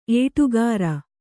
♪ ēṭugāra